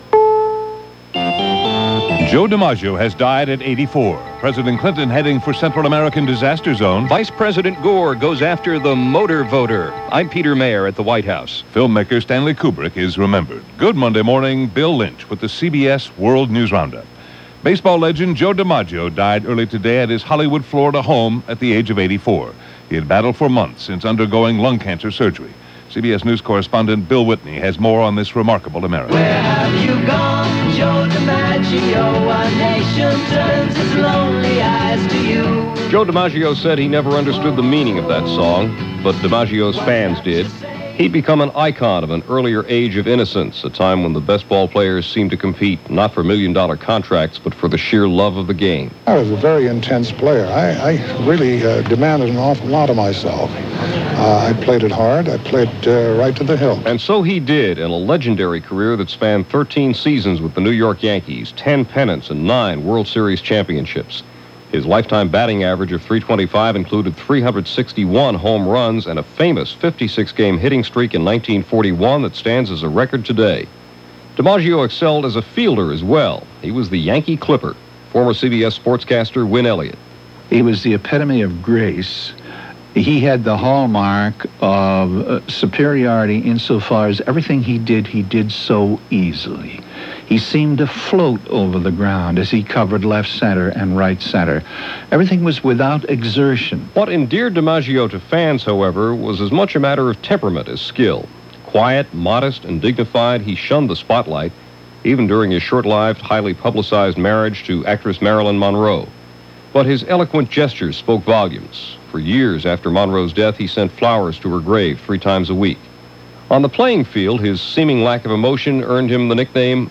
But this March 8th had much to absorb – and it was all presented by The CBS World News Roundup.